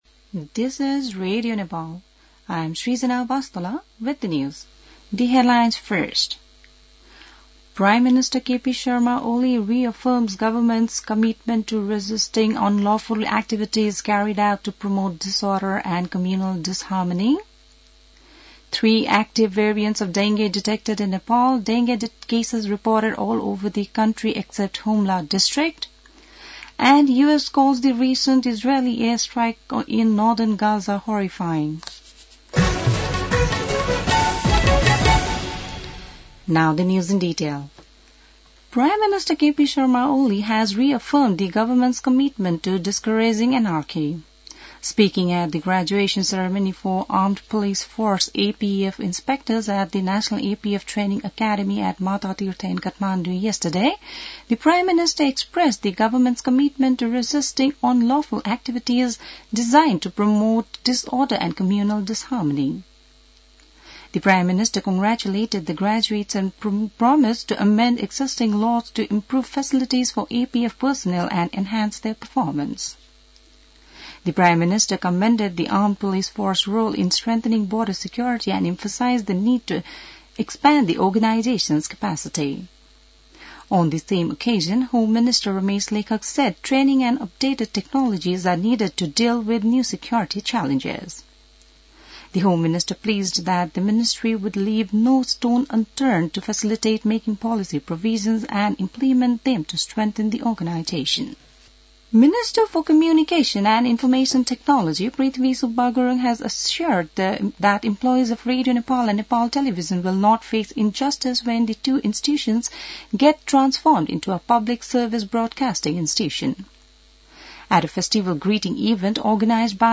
बिहान ८ बजेको अङ्ग्रेजी समाचार : १५ कार्तिक , २०८१